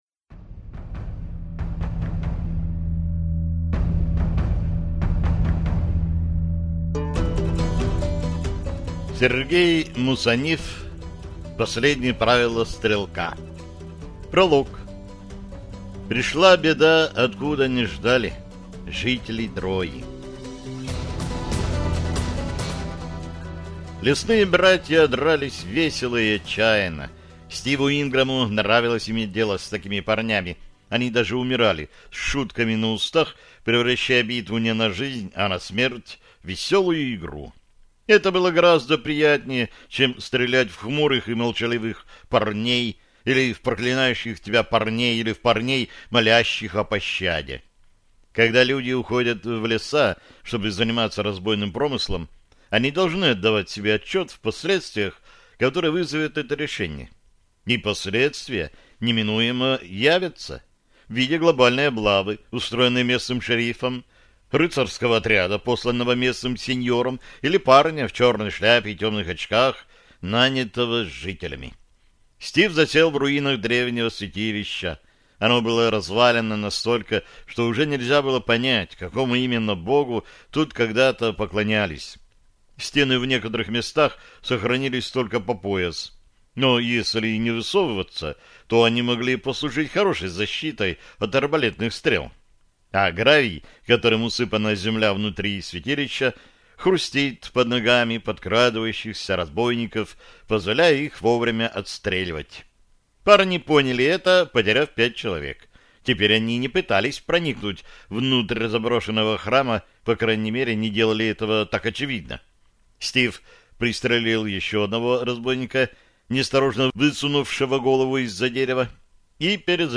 Аудиокнига Последнее правило стрелка | Библиотека аудиокниг